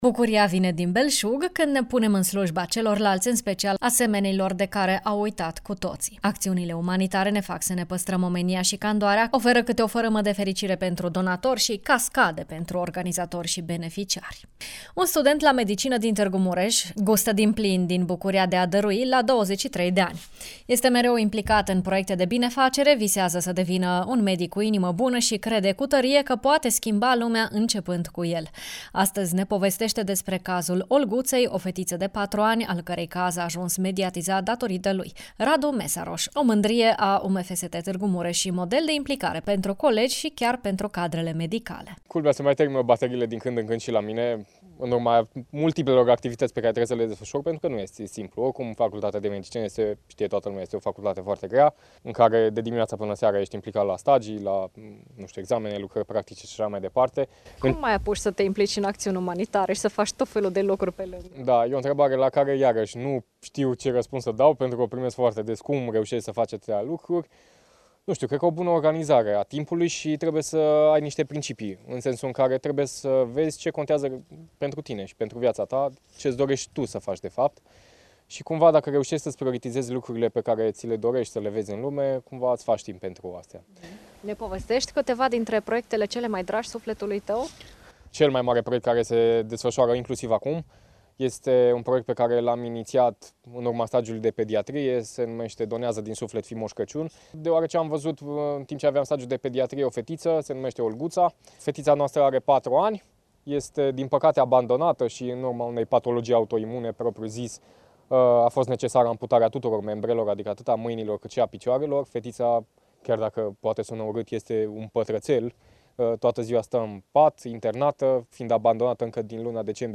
viitor medic